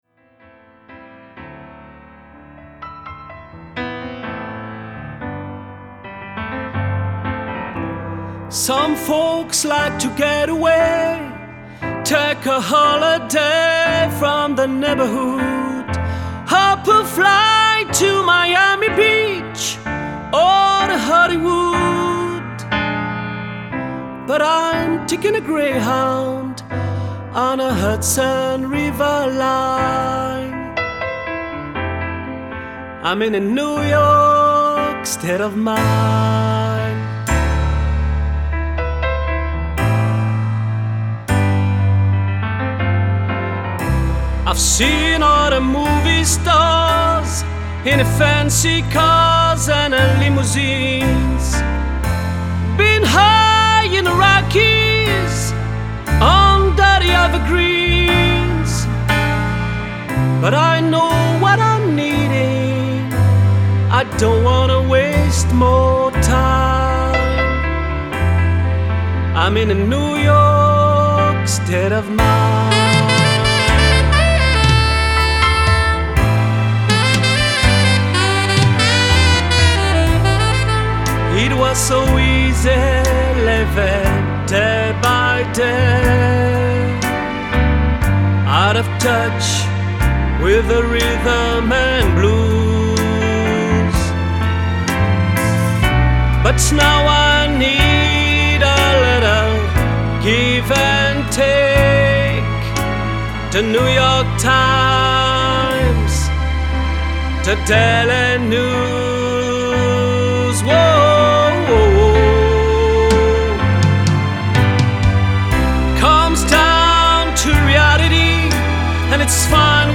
Démo Chant